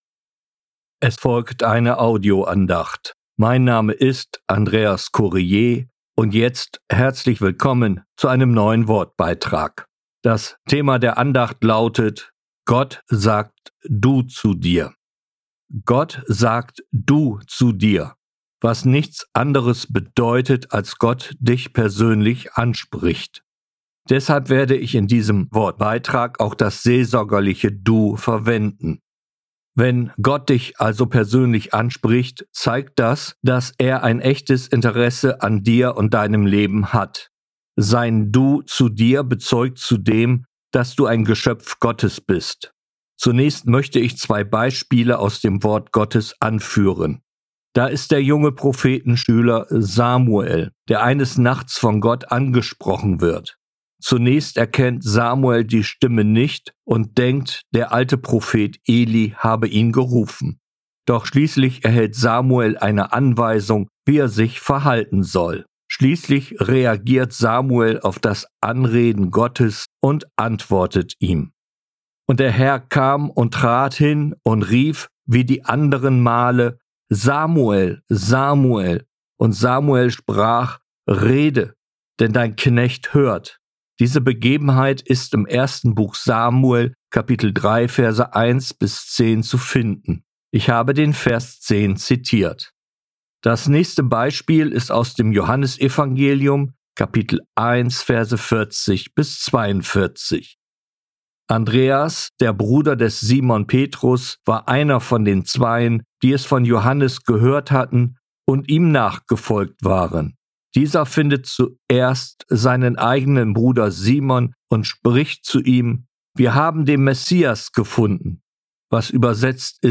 Gott sagt Du zu Dir, eine Audioandacht